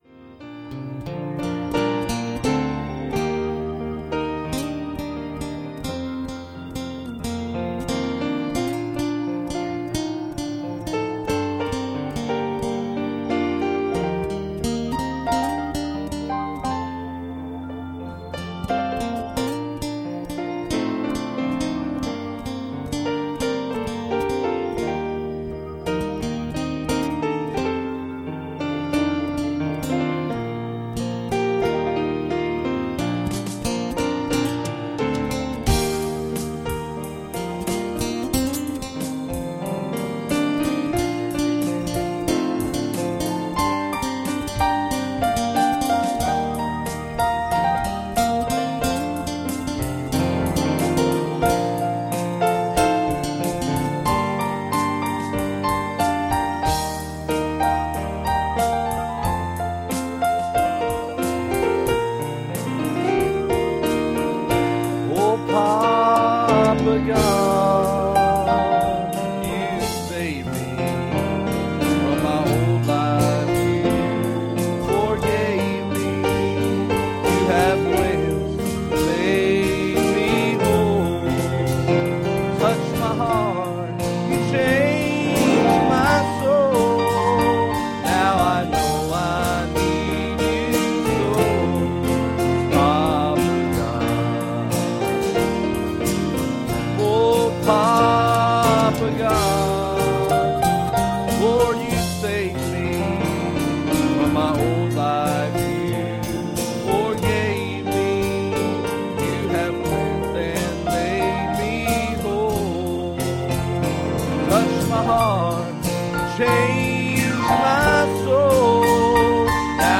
Passage: Genesis 15:13 Service Type: Sunday Morning